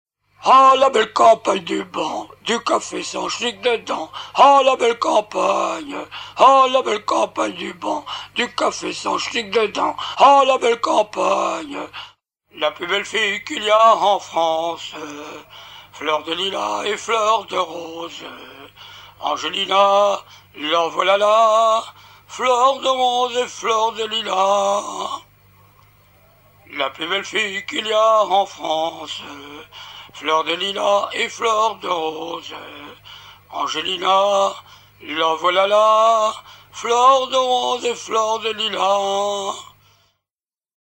Genre brève